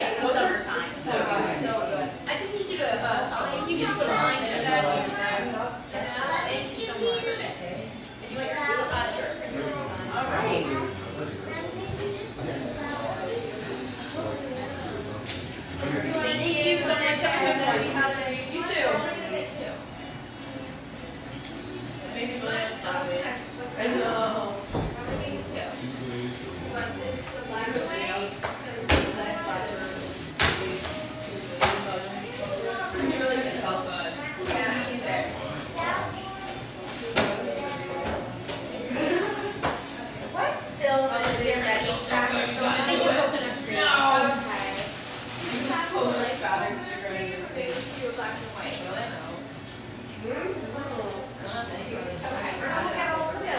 Field Recording 1
Time and Place: 1:32pm, 2/13, Doughnut Dolly in upstate NY
Recognizable Sounds: chatter, background music, paper rustling/boxes rustling